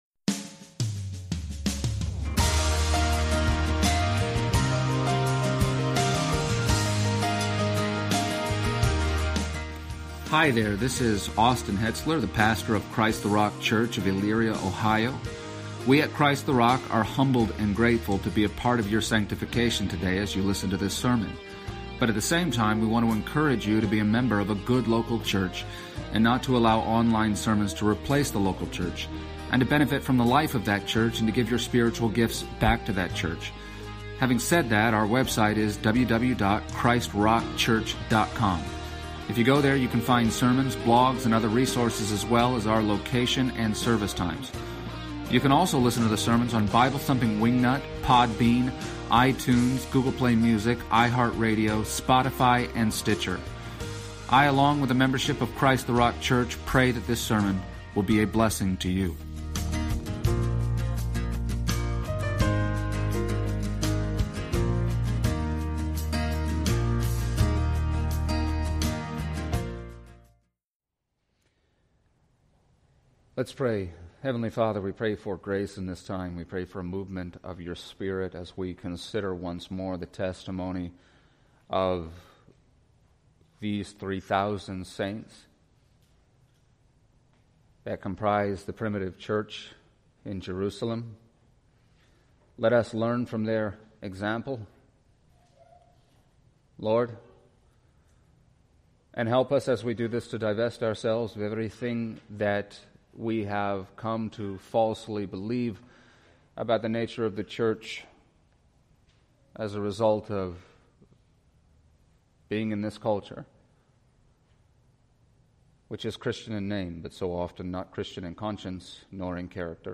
Passage: Acts 2:37-47 Service Type: Sunday Morning